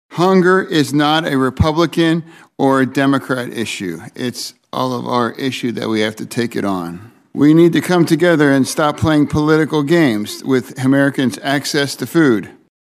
Partisan differences over limiting Supplemental Nutrition Assistance Program (SNAP) spending, which makes up more than 80% of the cost attributed to the federal farm bill, were on full display during a Senate Ag subcommittee hearing.